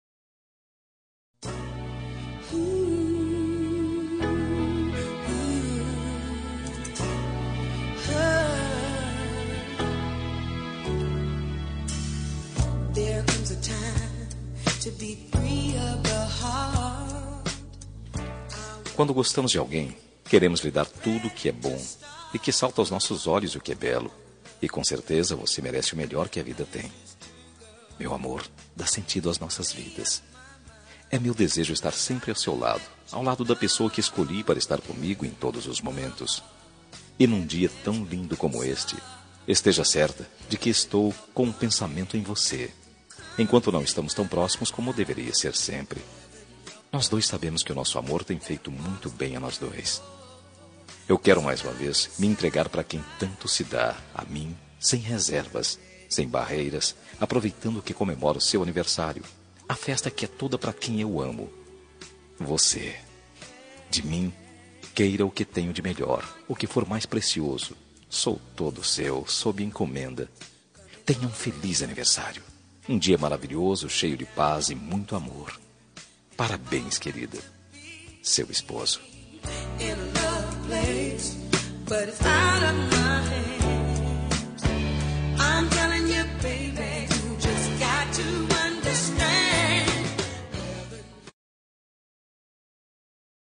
Telemensagem de Aniversário de Esposa – Voz Masculina – Cód: 1137